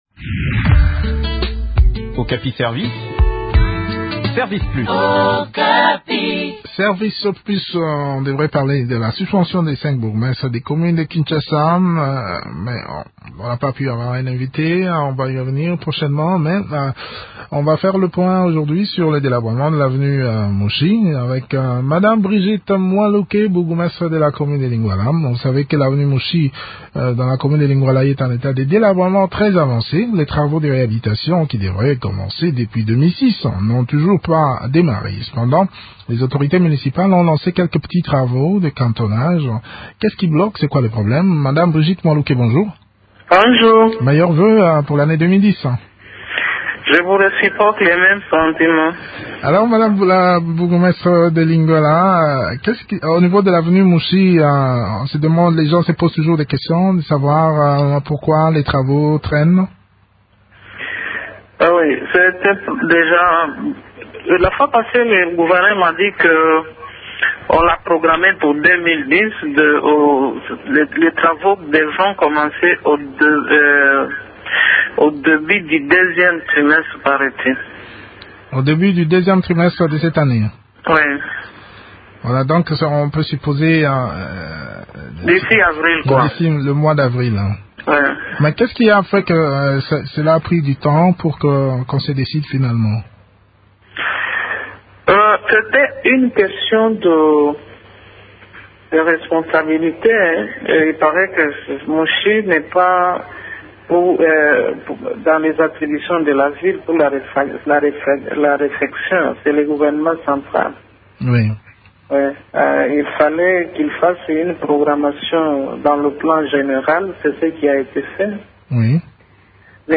s’entretient sur le sujet avec Brigitte Mualuke, bourgmestre de la commune de Lingwala.